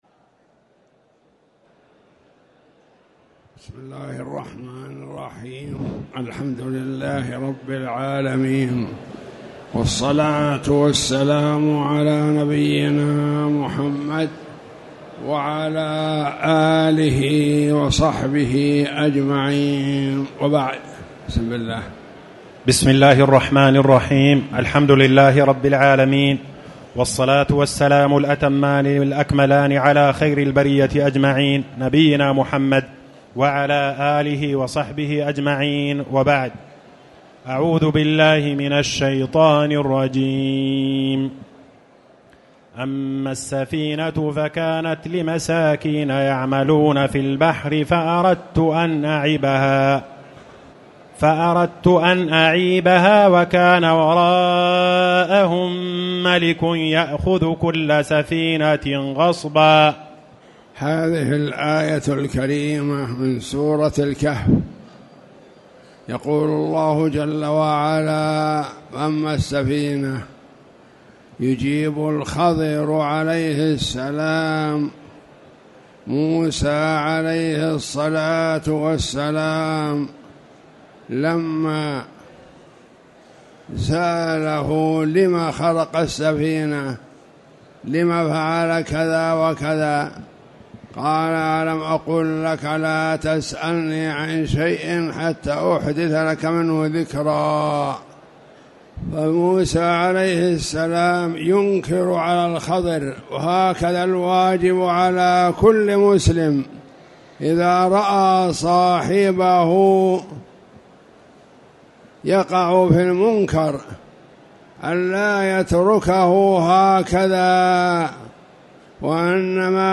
تاريخ النشر ١٢ صفر ١٤٣٩ هـ المكان: المسجد الحرام الشيخ